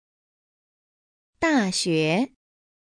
dàxué